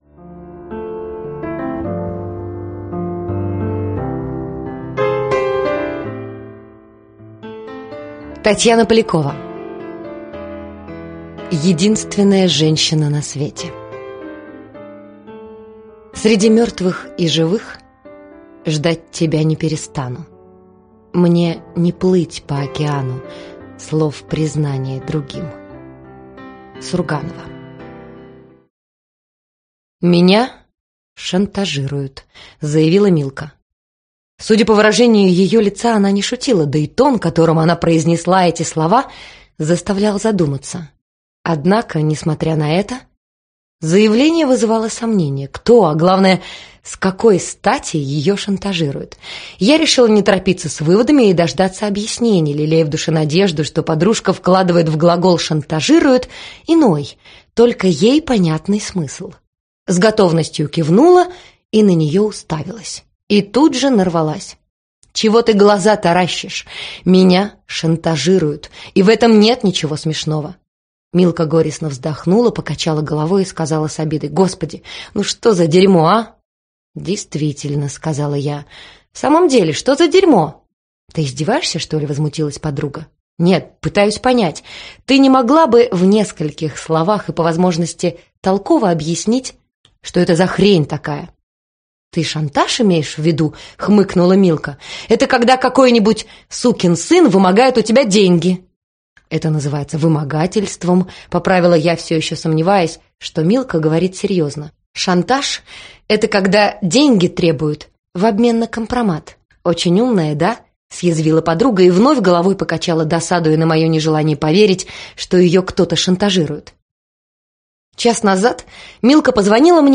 Аудиокнига Единственная женщина на свете | Библиотека аудиокниг